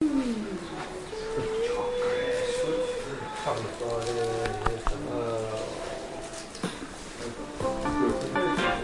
洛杉矶X希尔顿酒店大堂
描述：从乘坐电梯到洛杉矶机场希尔顿酒店的一楼大厅开始。用EdirolR9录制。
标签： 酒店式大堂 现场录音
声道立体声